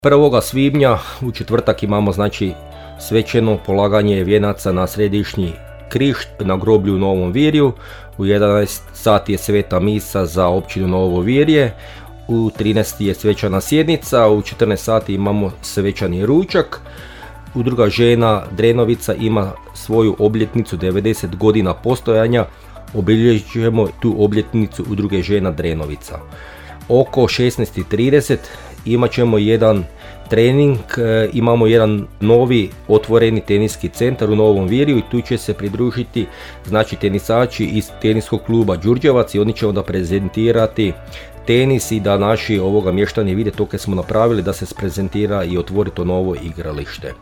– govori načelnik Remetović i svim Josipama i Josipima čestitao imendan, a mještankama i mještanima Novog Virja, Dan općine.